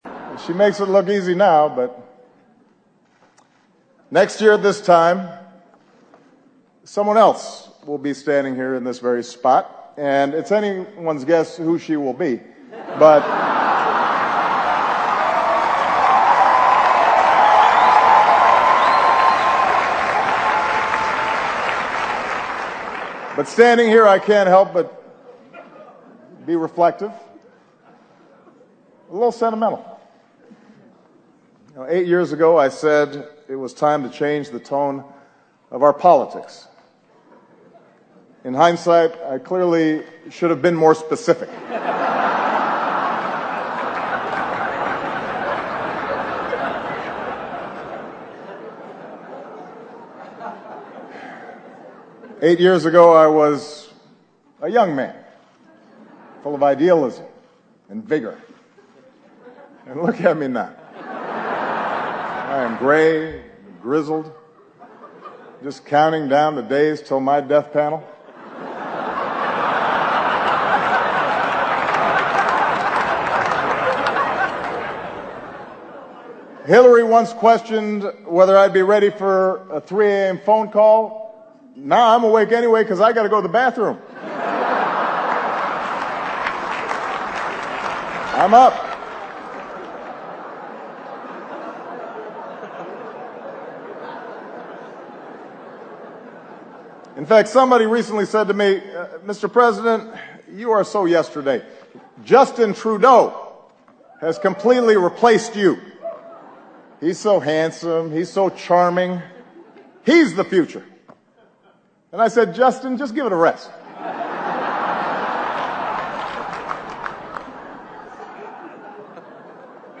欧美名人演讲 第80期:奥巴马任内末次白宫记者晚宴演讲(2) 听力文件下载—在线英语听力室